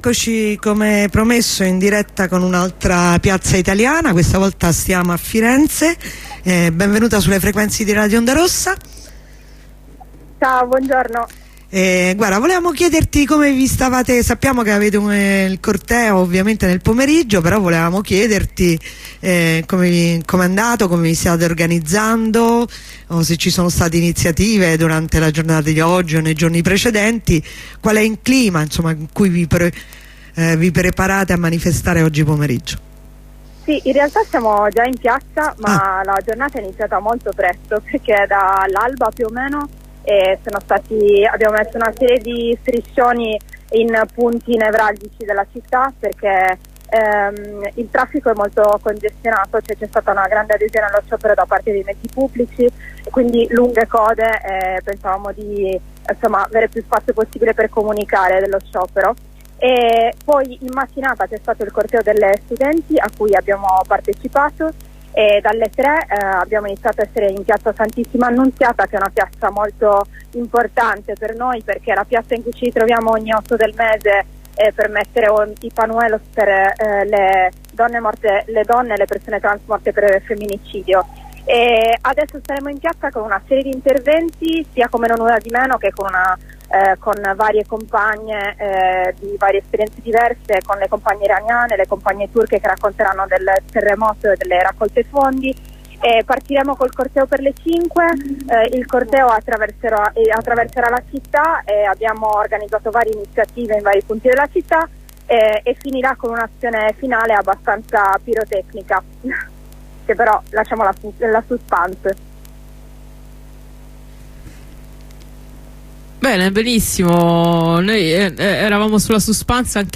Una compagna di L.E.A. Berta racconta l'azione di protesta di questa mattina nelle vie del centro di Roma per denunciare il sistema della fast fashion: la moda è una questione di sfruttamento dell'ambiente,di sfruttamento di genere e di sfruttamento di specie. Le grandi catene dell'abbigliamento sono responsabili di violenza e oppressione quotidiana.